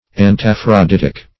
Meaning of antaphroditic. antaphroditic synonyms, pronunciation, spelling and more from Free Dictionary.
antaphroditic.mp3